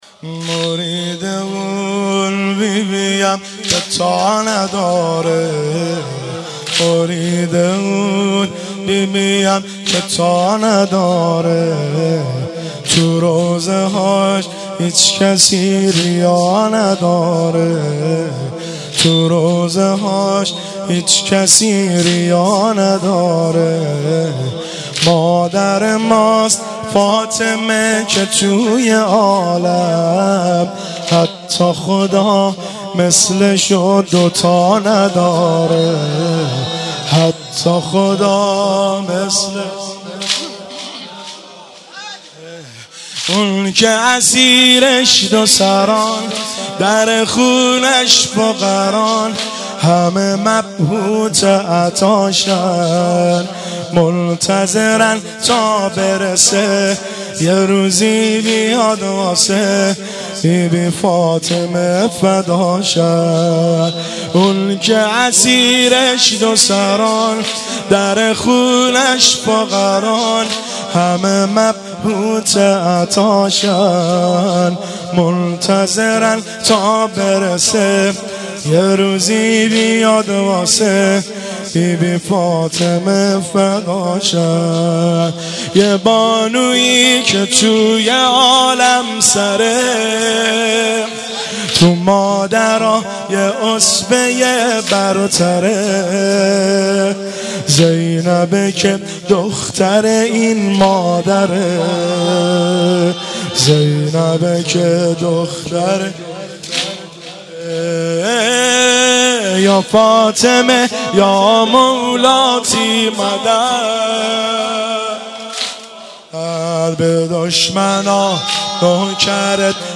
فاطمیه 96 - واحد - مرید اون بی بی ام